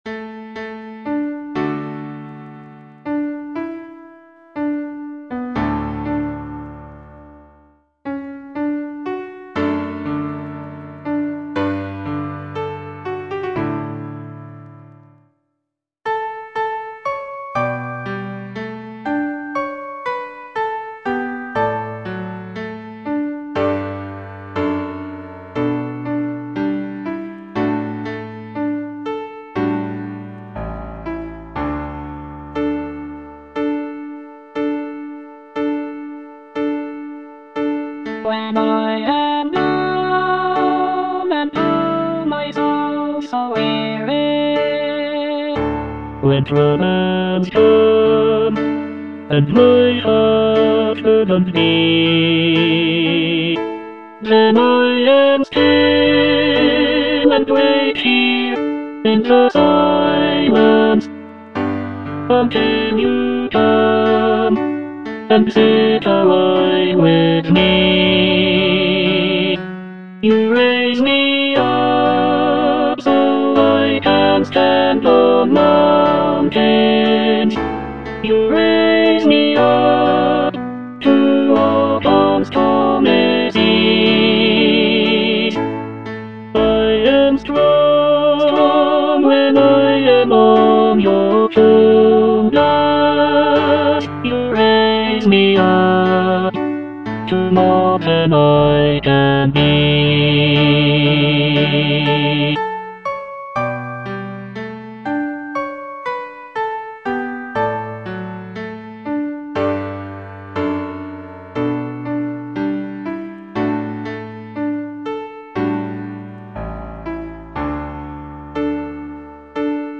Tenor (Emphasised voice and other voices)